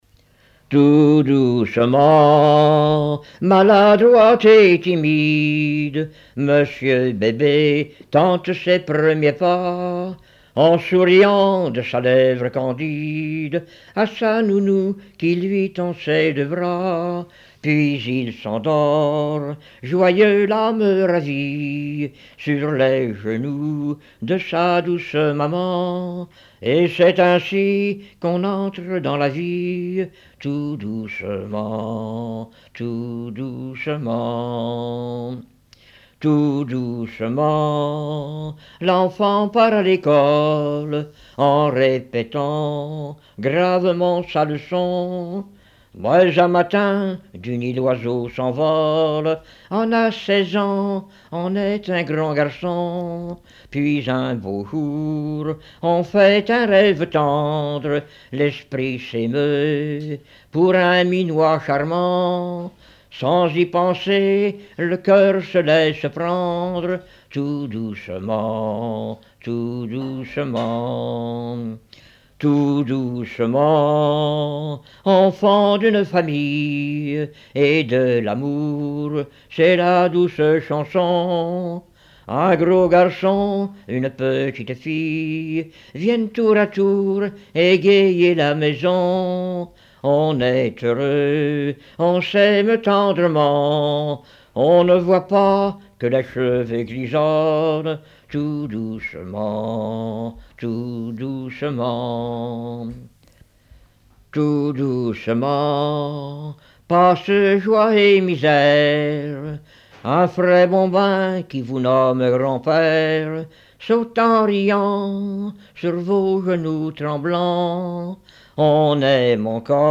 Genre strophique
Chansons du début XXe siècle
Catégorie Pièce musicale inédite